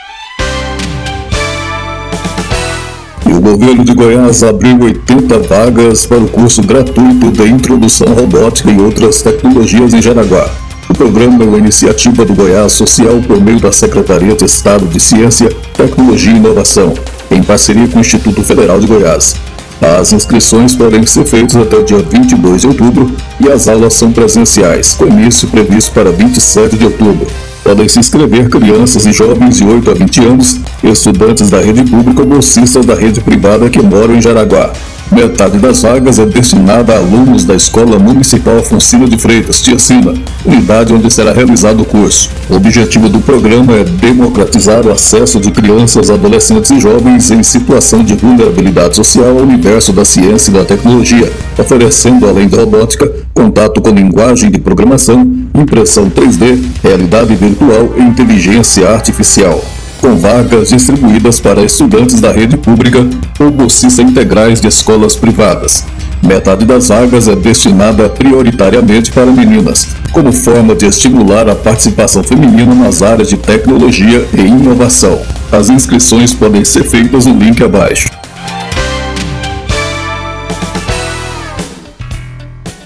VINHETA-ROBOTICA.mp3